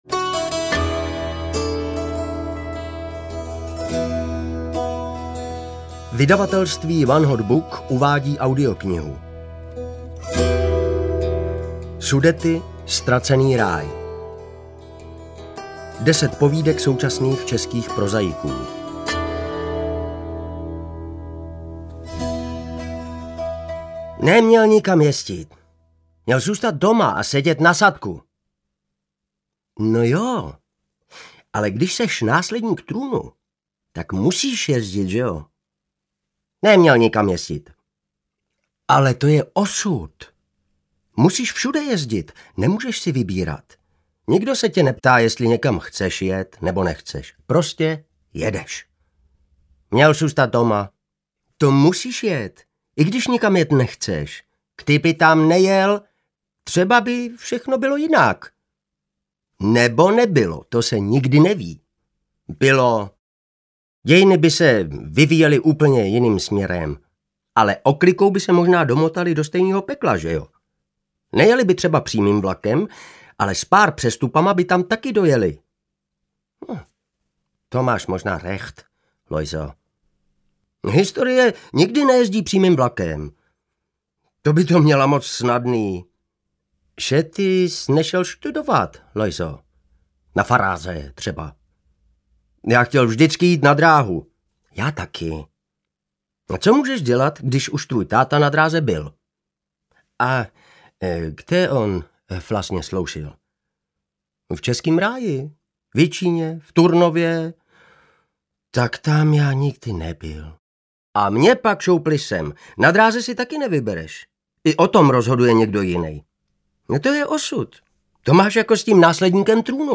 Sudety: Ztracený ráj audiokniha
Ukázka z knihy
sudety-ztraceny-raj-audiokniha